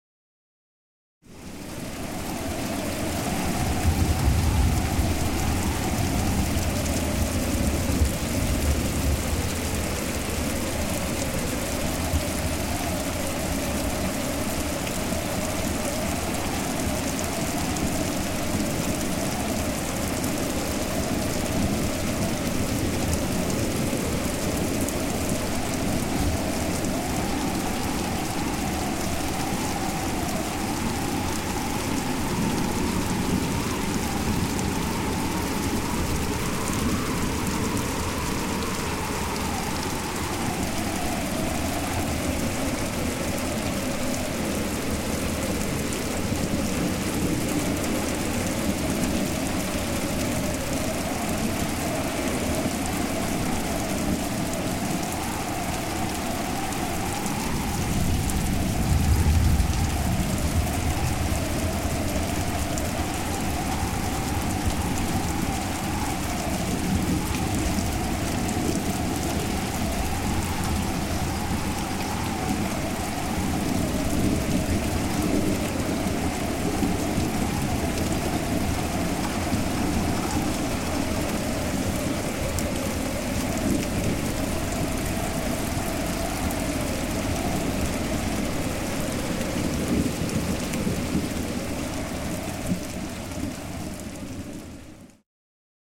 Шум осеннего дождя в ветреный день